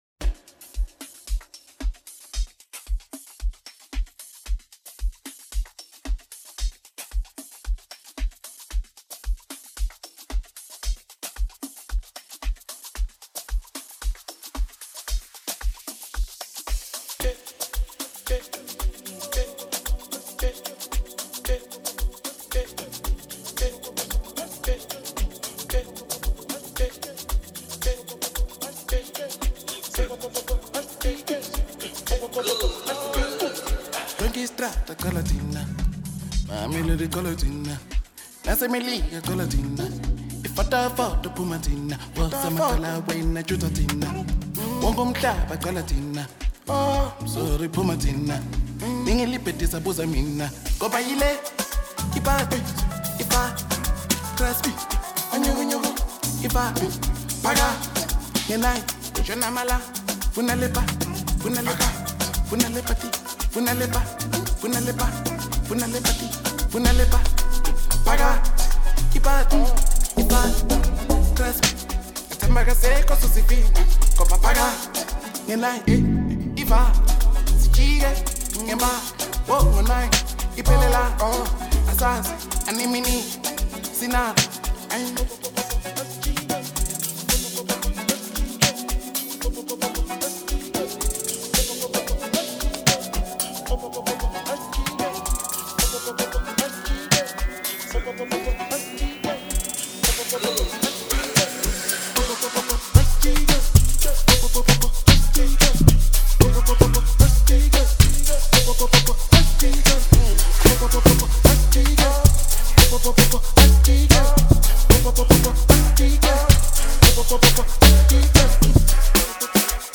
two amazing female artists